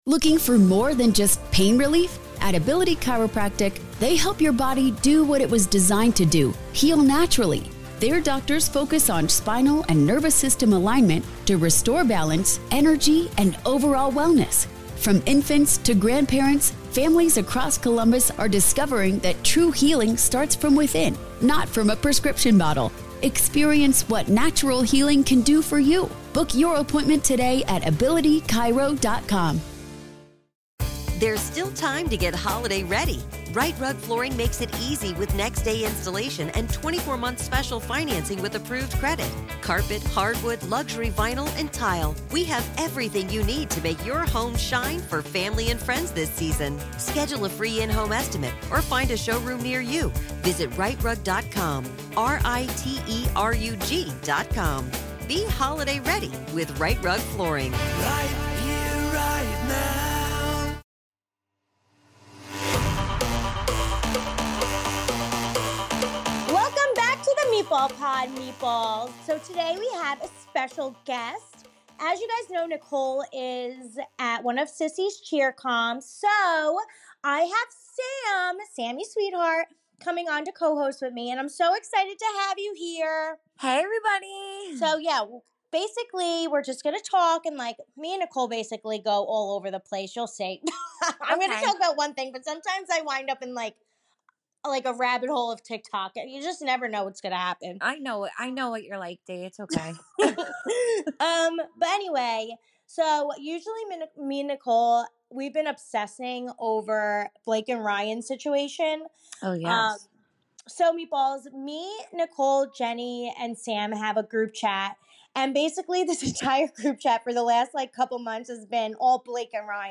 Deena and Sam talk about their most memorable celebrity run-ins, funny Jersey Shore prank memories, and Sam’s new pregnancy.